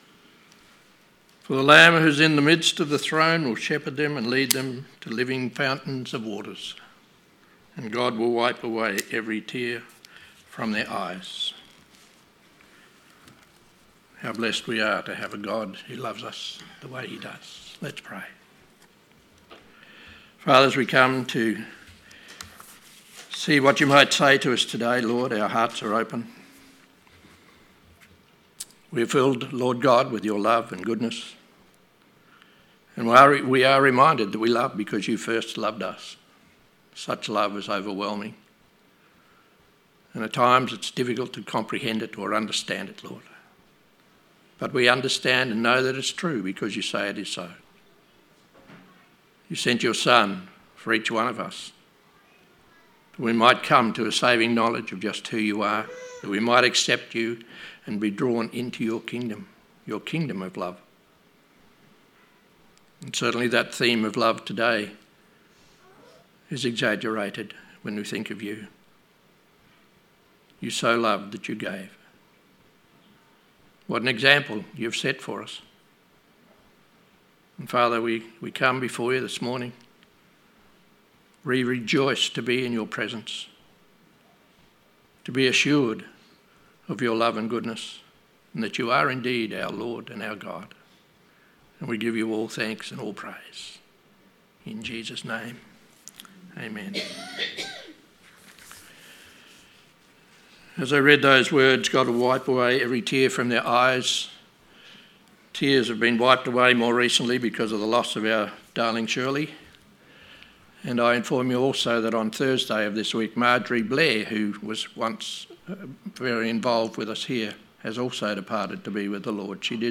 Sermon 11th May 2025